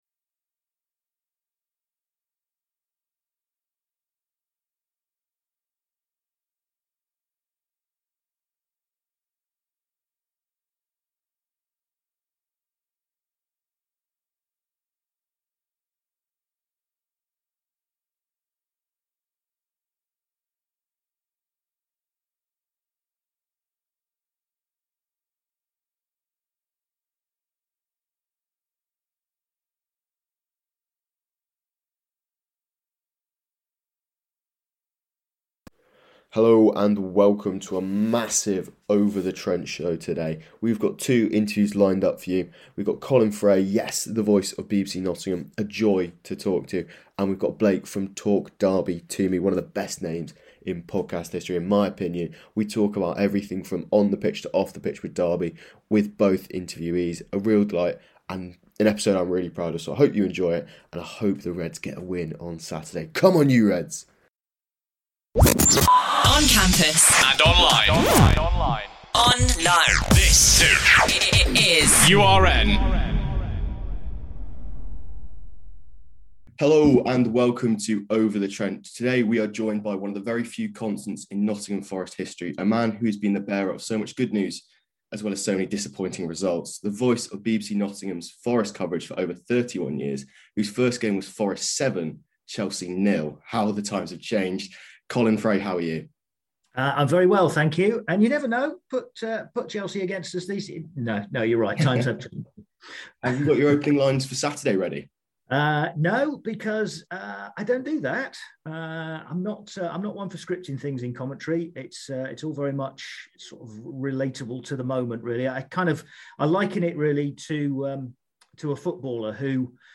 Nottingham Forest vs Derby County Preview